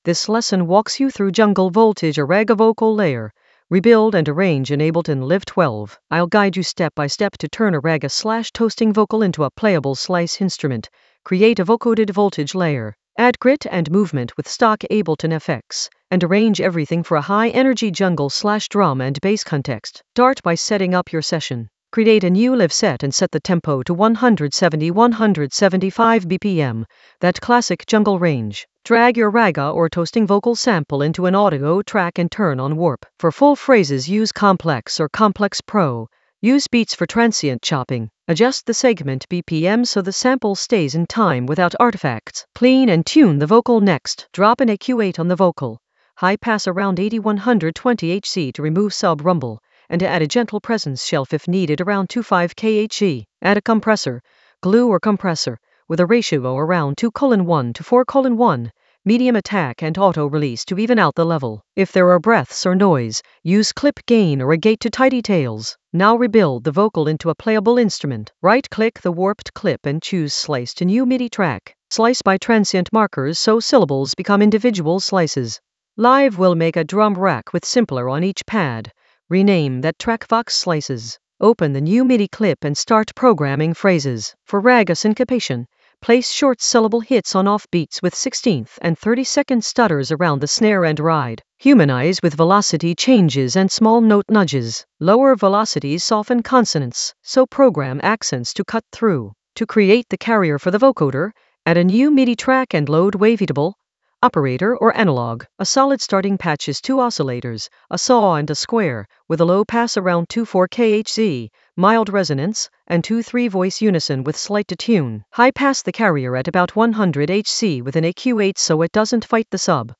An AI-generated intermediate Ableton lesson focused on Jungle Voltage a ragga vocal layer: rebuild and arrange in Ableton Live 12 in the FX area of drum and bass production.
Narrated lesson audio
The voice track includes the tutorial plus extra teacher commentary.